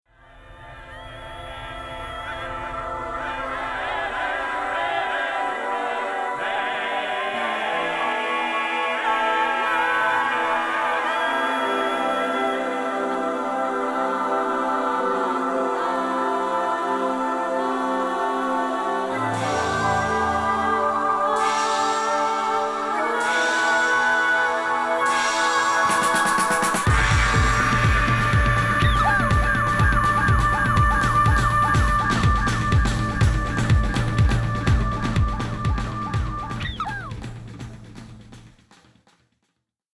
音像が見えそうなくらい強烈なタッチで描かれたエクスペリメンタル・ダンスサウンド！！